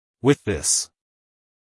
with-this-us-male.mp3